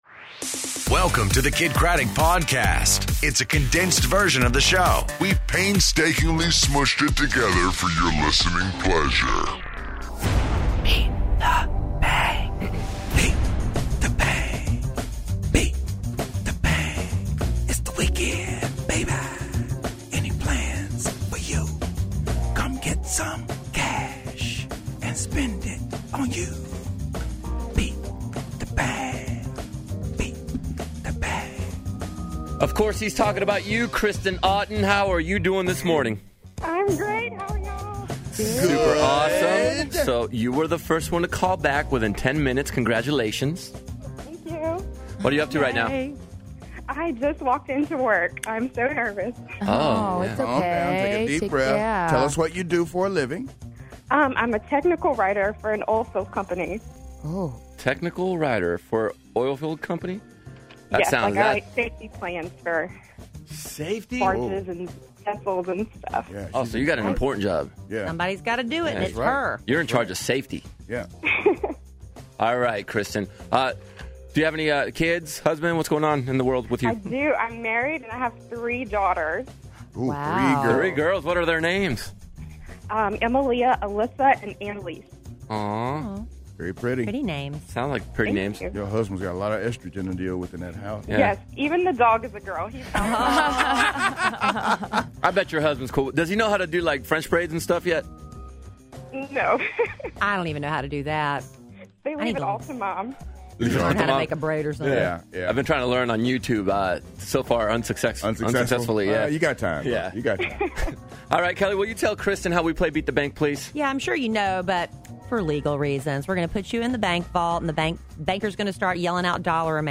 Feel Good Friday, Theo Von In Studio, And A Homecoming King With A Great Heart